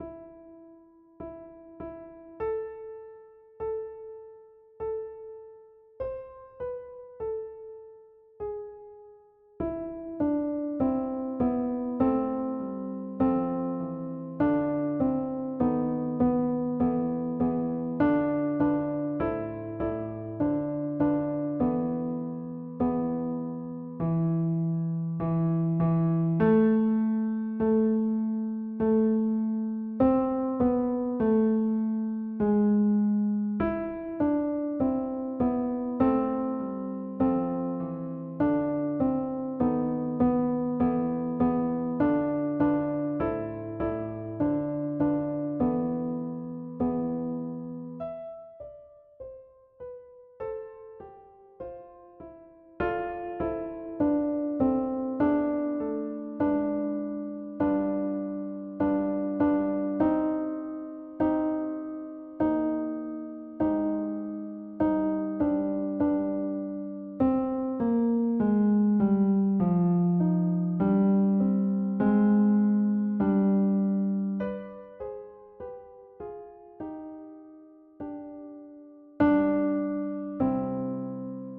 Répétition SATB par voix.
Chaque voix jouée au piano
Alto
Très belle interprétation, on devine les 4 voix.